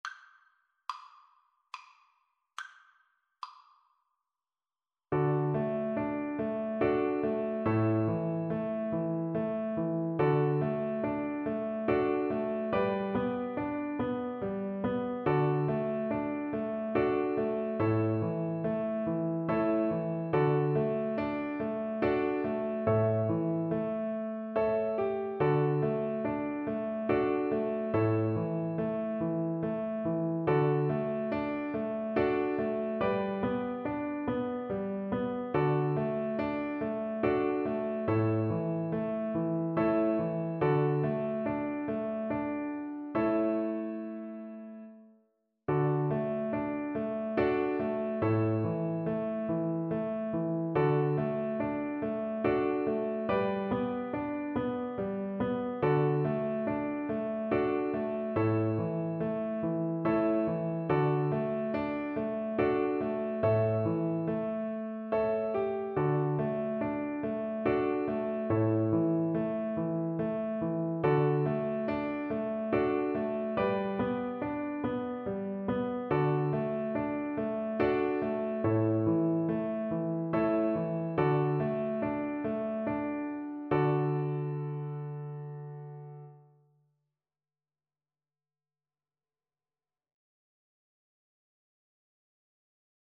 Moderato
3/4 (View more 3/4 Music)